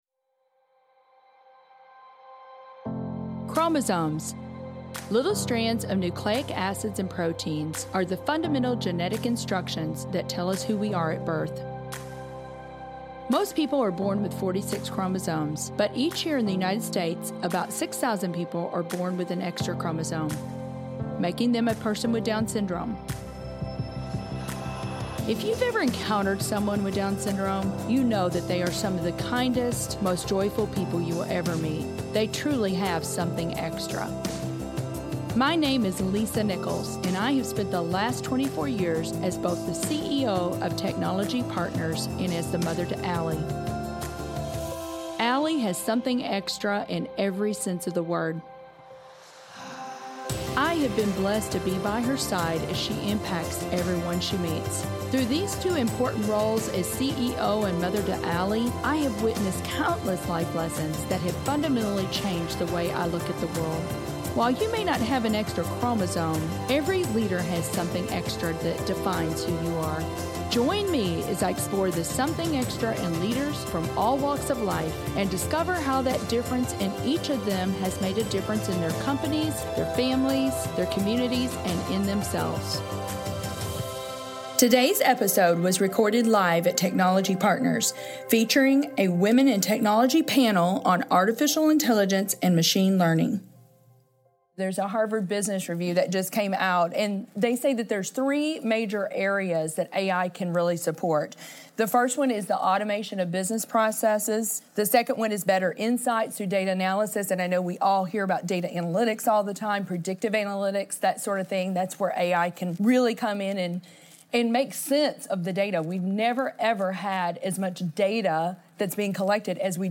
Something Extra w/ Women In Technology Live Panel